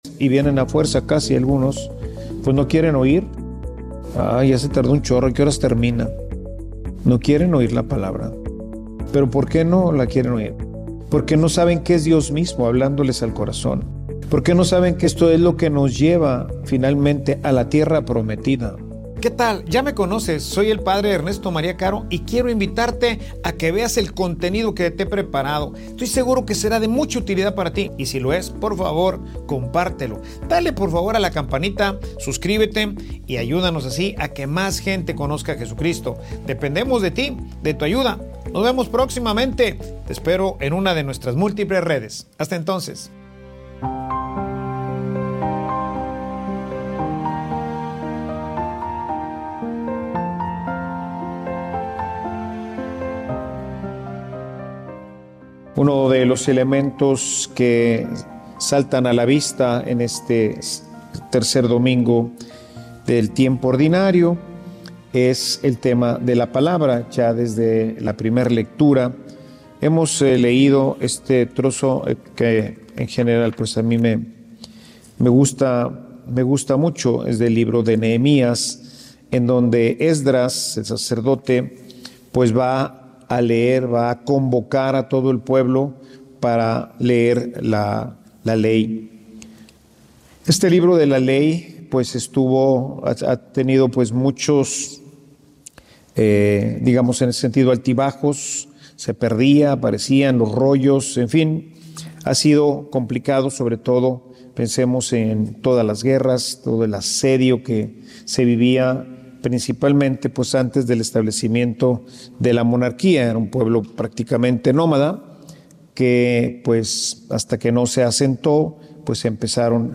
Homilia_Ya_pero_todavia_no.mp3